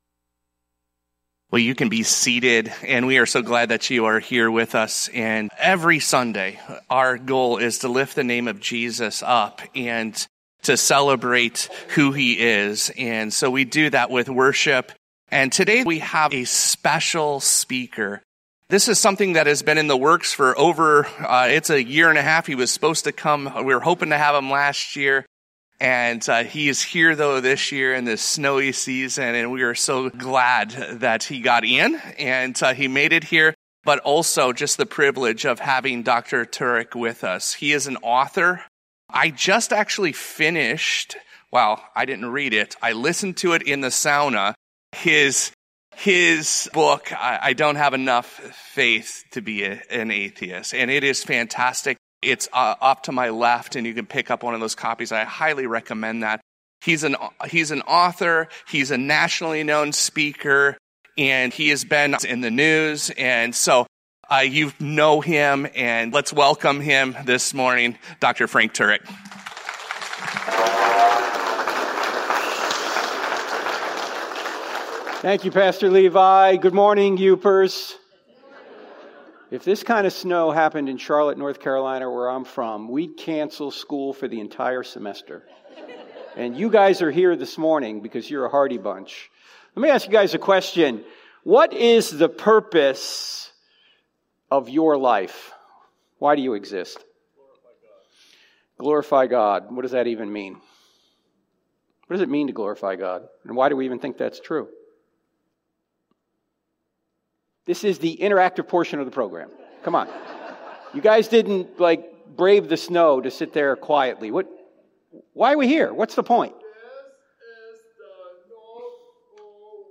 A message between sermon series, by a guest speaker, or for a special event or holiday.